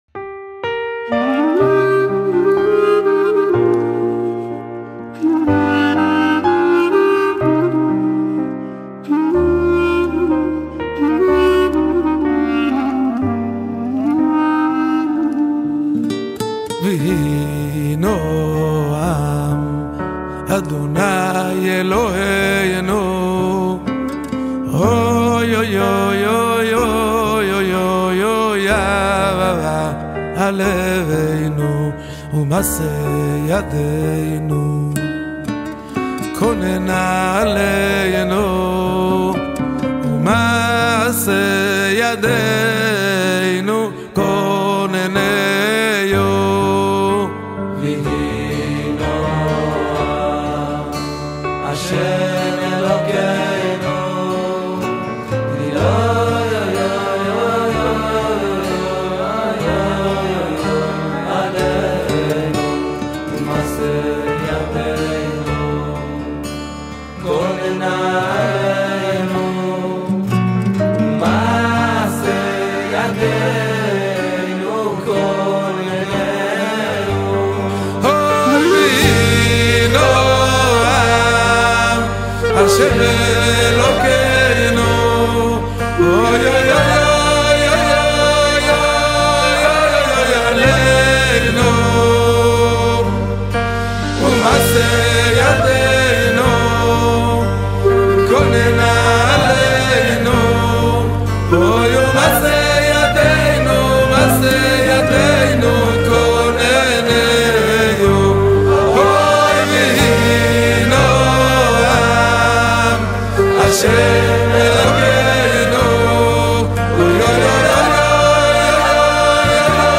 Исполнение благословений, которые произносят при зажжении ханукальных свечей